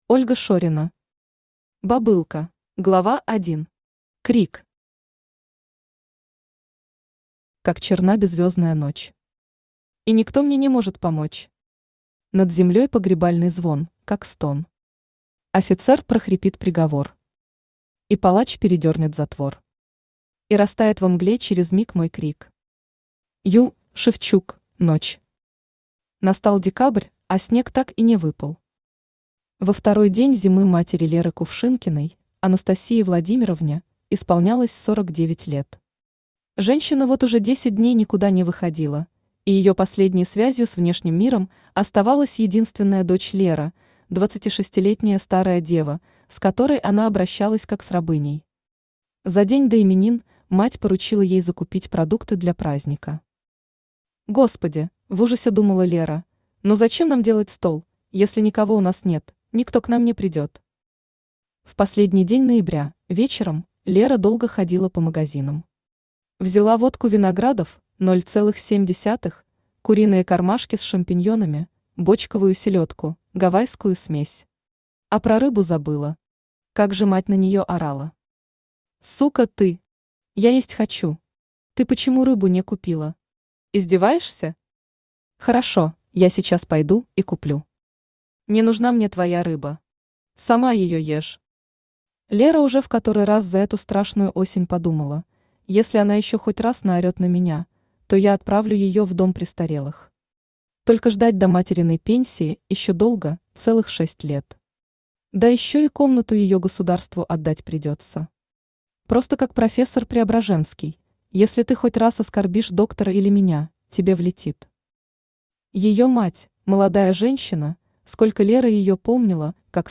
Аудиокнига Бобылка | Библиотека аудиокниг
Aудиокнига Бобылка Автор Ольга Евгеньевна Шорина Читает аудиокнигу Авточтец ЛитРес.